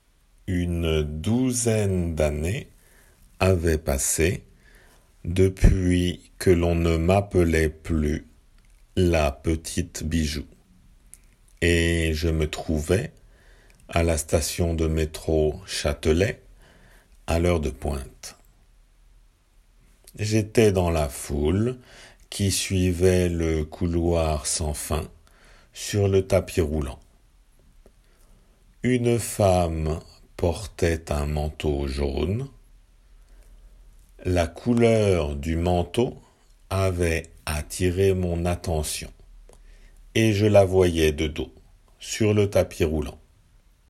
読まれる文